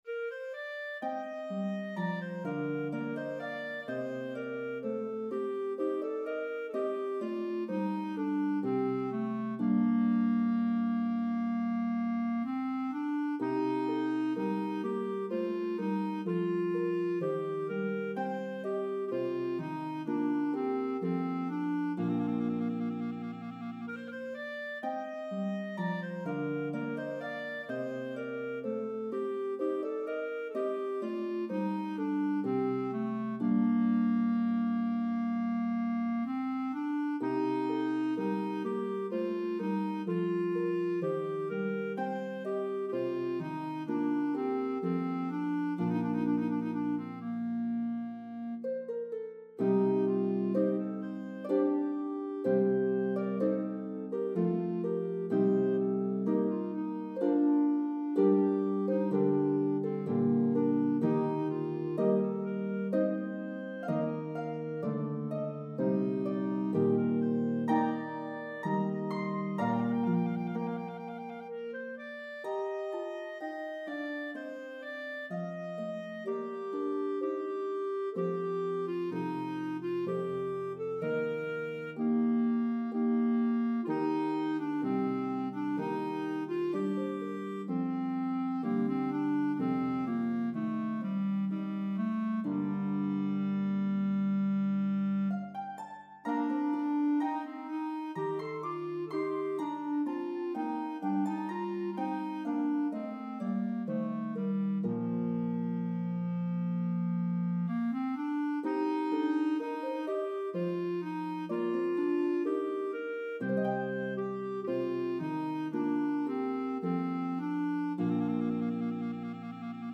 Italian Baroque style
lovely slow air